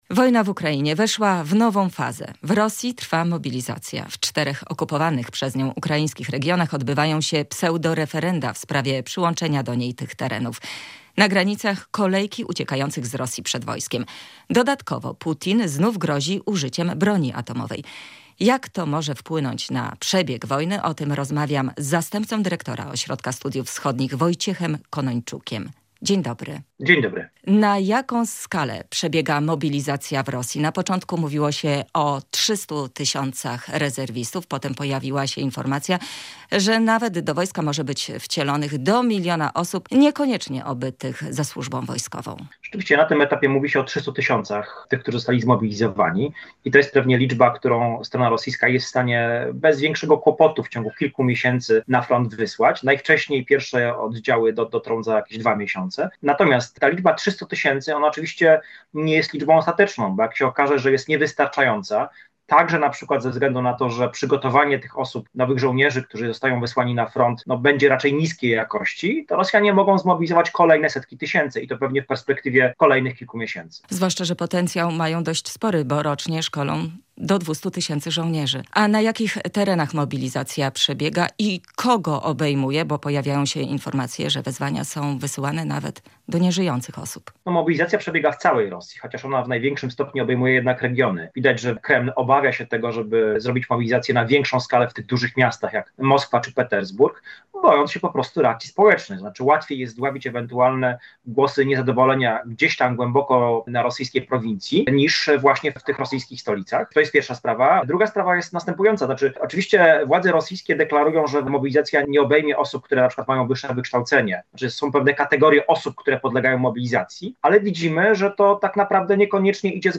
studio